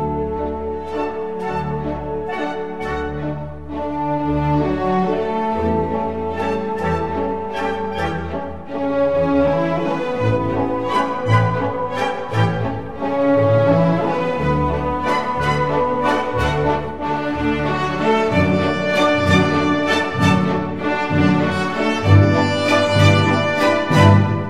Música Clasica
el vals más conocido.